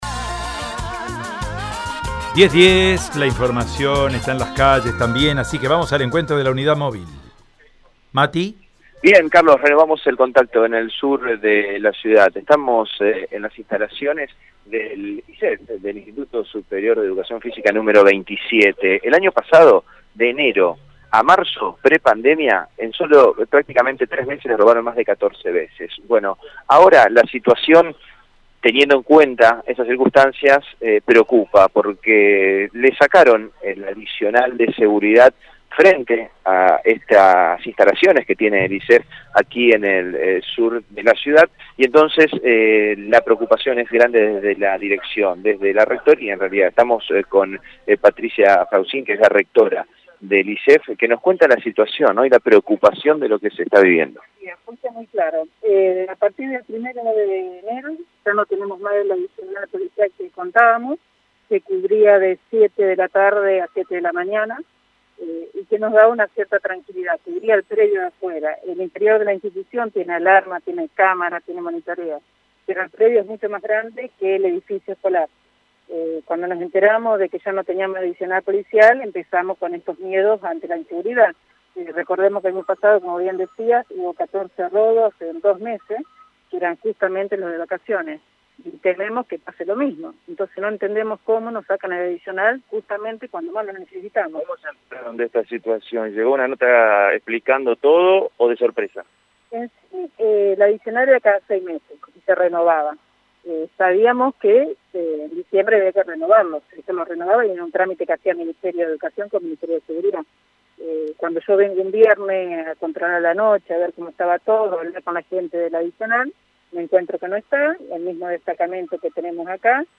En dialogo con el móvil de Radio EME